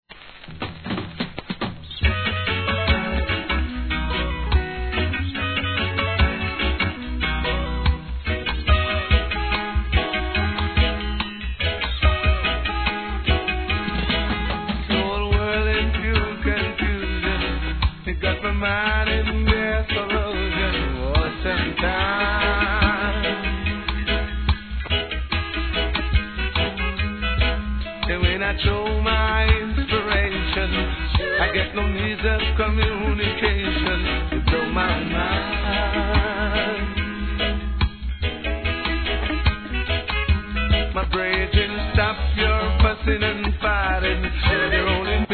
REGGAE
洗練されたMUSICで世界に向けたラスタ・メッセージ!!